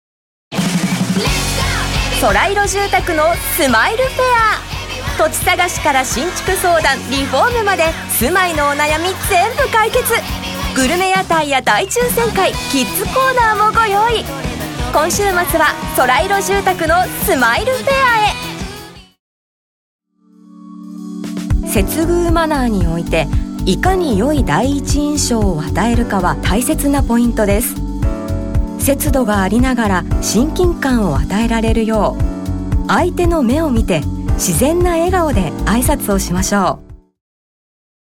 • ナレーター